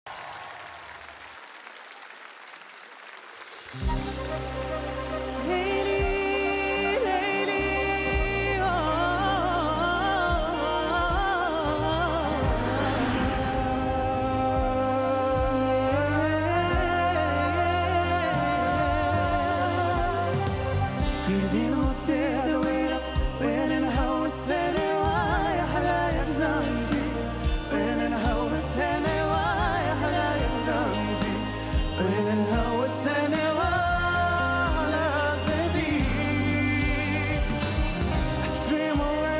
Гала-концерт от 06.12.2024г.